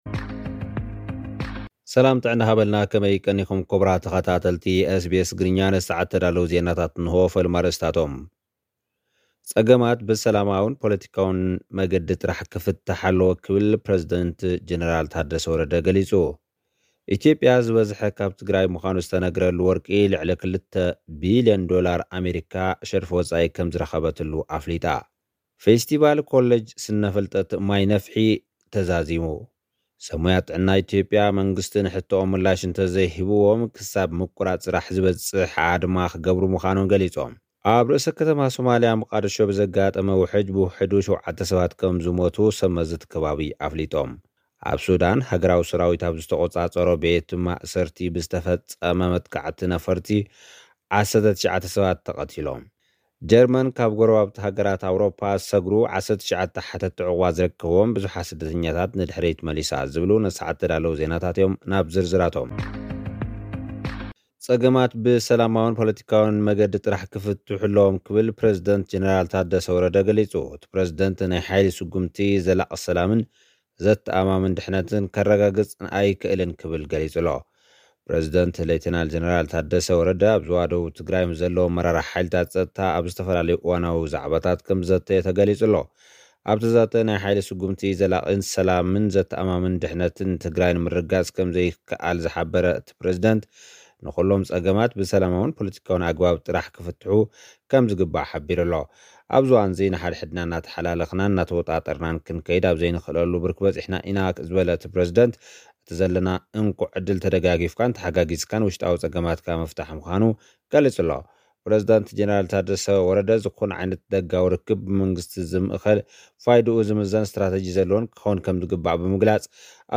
ልኡኽና ዝሰደደልና ጸብጻብ፡ ፀገማት ብሰላማዊን ፖለቲካዊን መገዲ ጥራሕ ክፍታሕ ኣለዎ ክብል ፕረዚደንት ጀነራል ታደሰ ወረደ ገሊጹ።